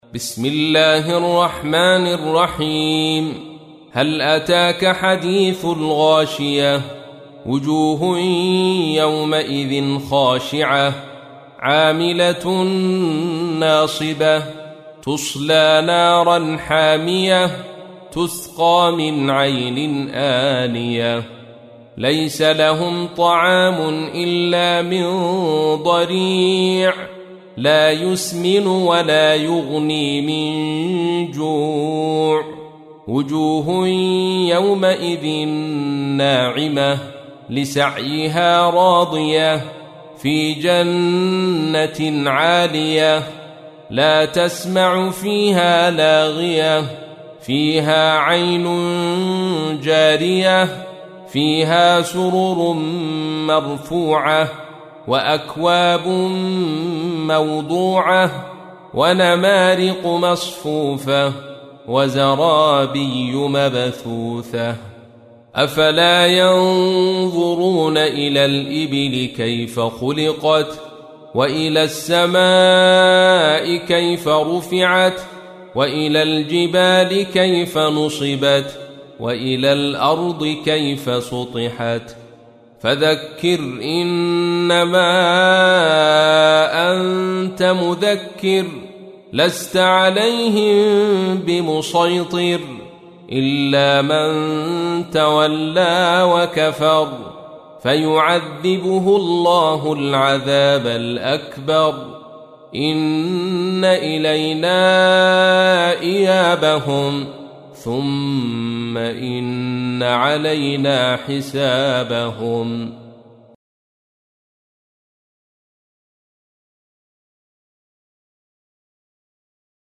تحميل : 88. سورة الغاشية / القارئ عبد الرشيد صوفي / القرآن الكريم / موقع يا حسين